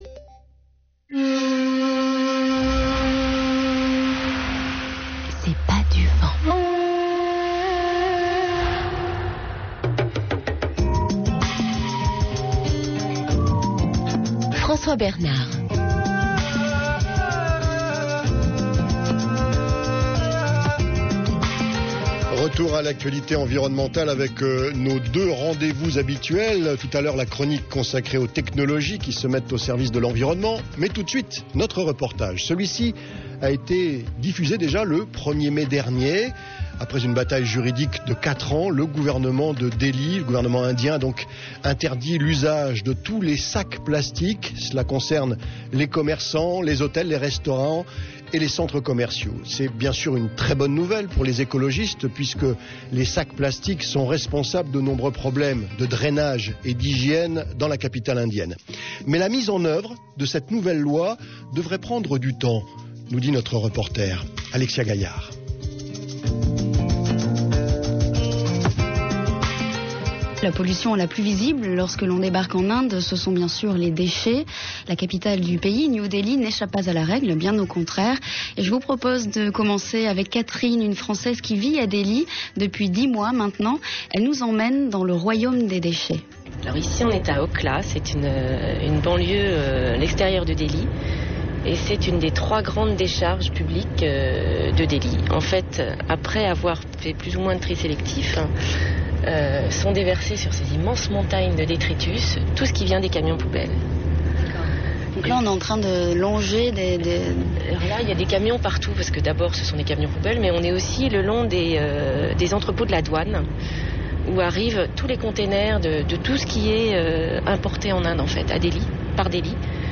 Le défi est désormais de réveiller la conscience environnementale des Indiens. Un reportage